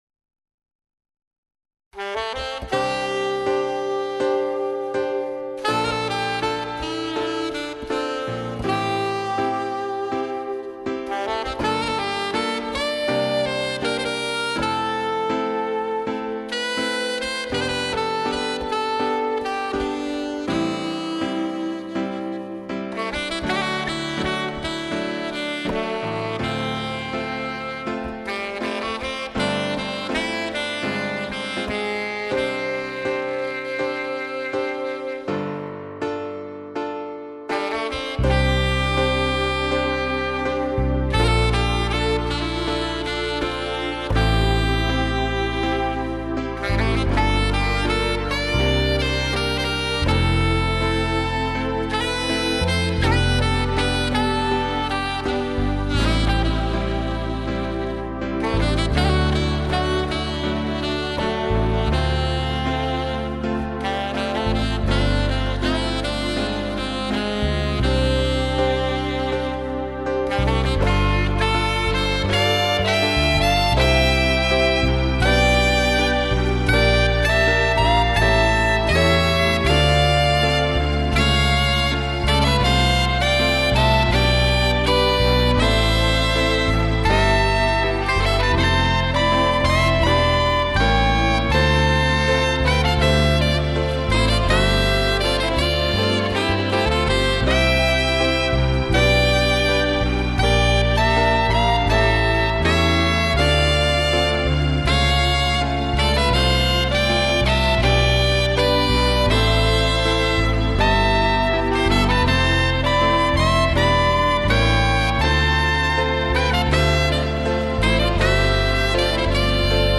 색소폰으로 연주한
2006-09-21 애드립이 수준급이시네요.